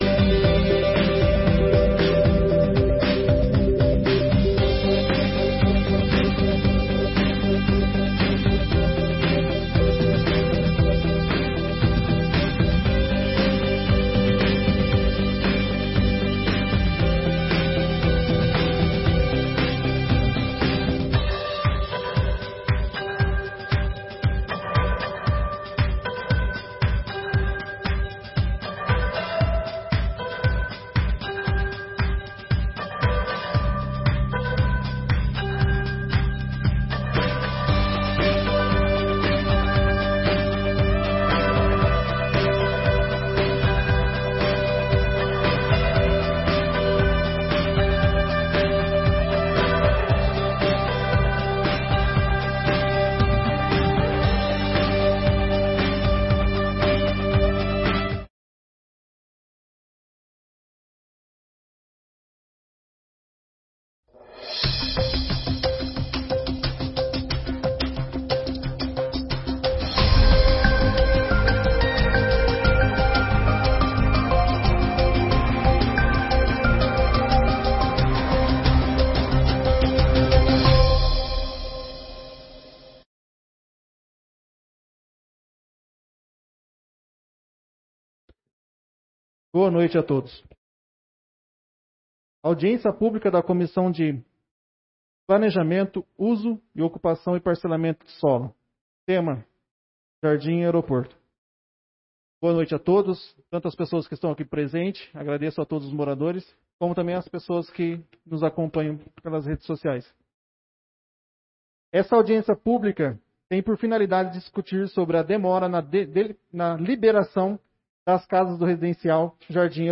Audiências Públicas de 2022